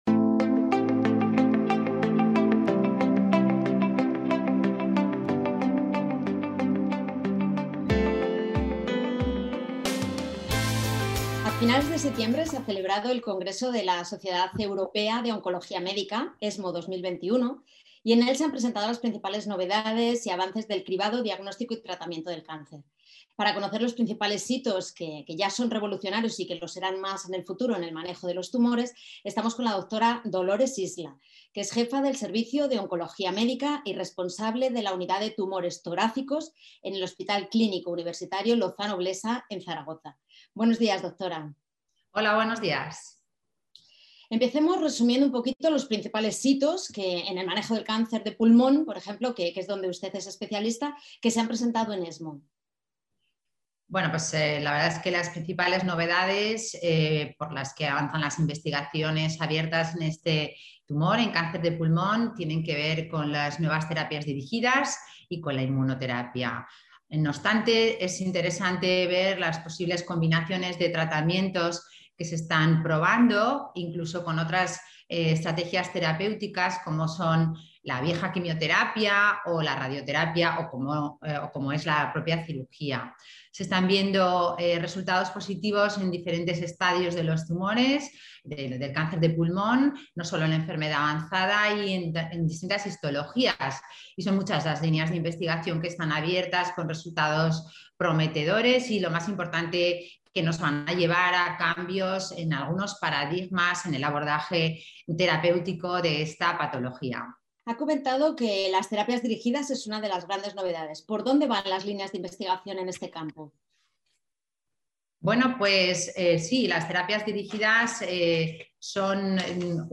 Podcast de la entrevista.